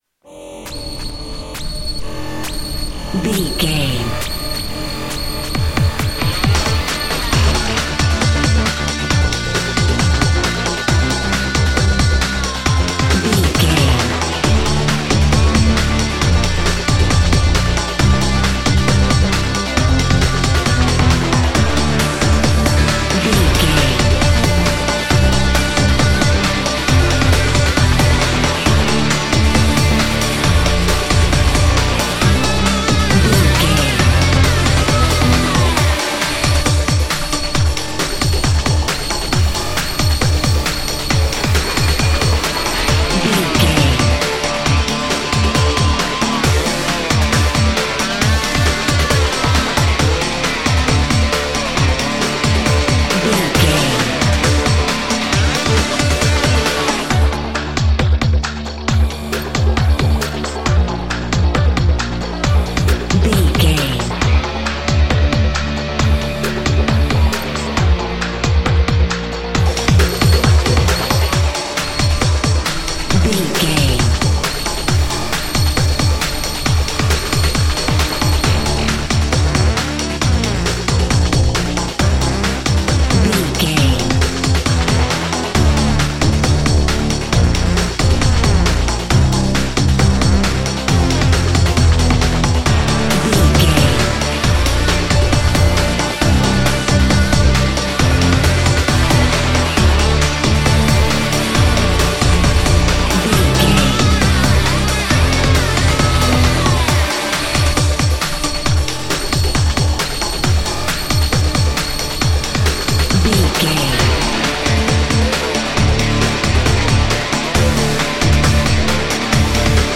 Aeolian/Minor
drum machine
synthesiser
hard rock
lead guitar
bass
drums
aggressive
energetic
intense
nu metal
alternative metal